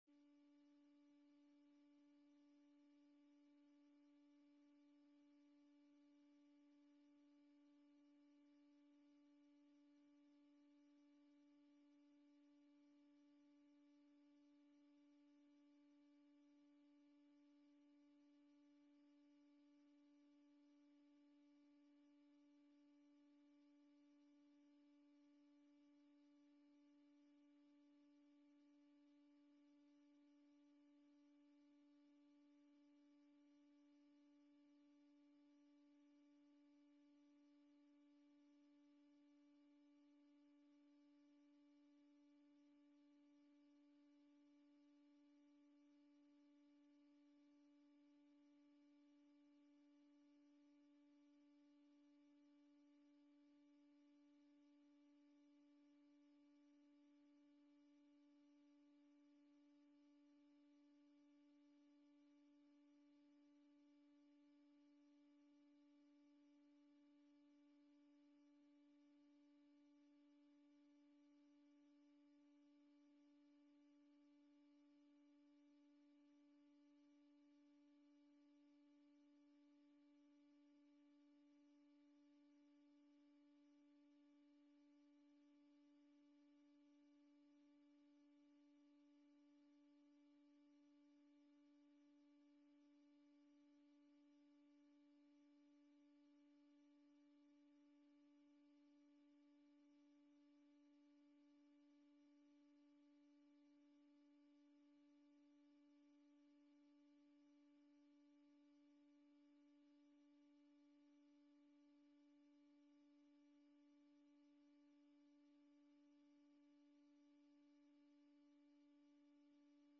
De vergadering wordt gehouden in zaal 008 in het Atrium met in achtneming van de 1,5 meter regel.